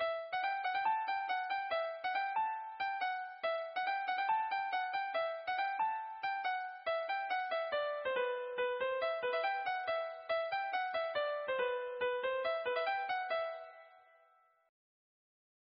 Garnache (La)
danse : branle : courante, maraîchine